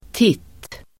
Uttal: [tit:]